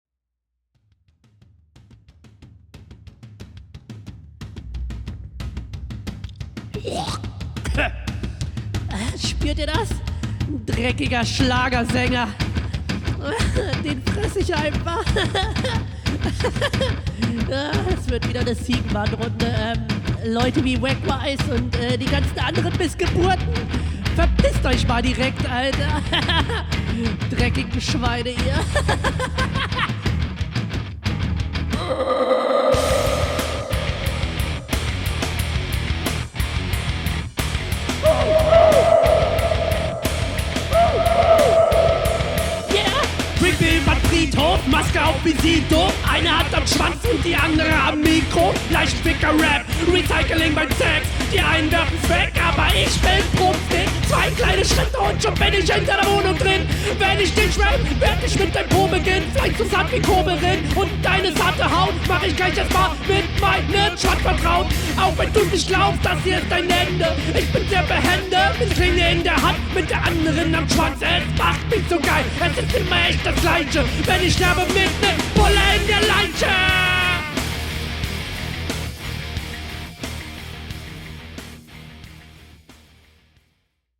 Dieses Ziegenartige Schreien zum Beateinsteig fand ich geil.
Wieder sehr interessanter Beat.